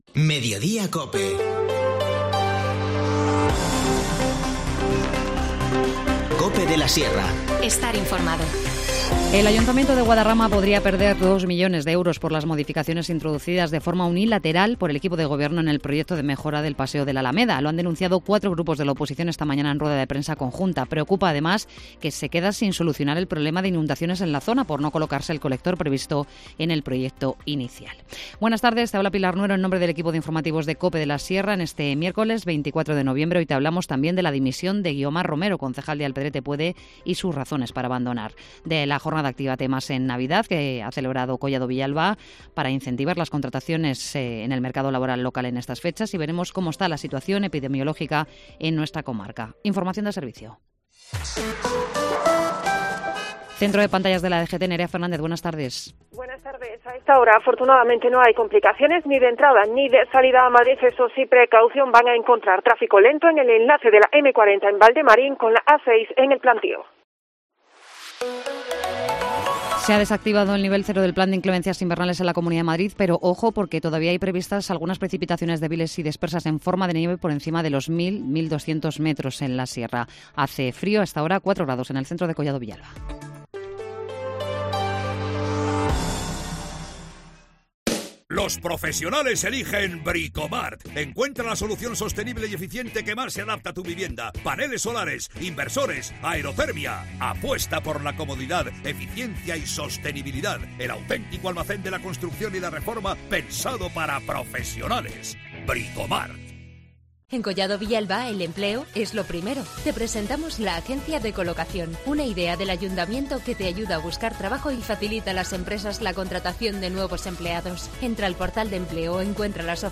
Informativo Mediodía 24 noviembre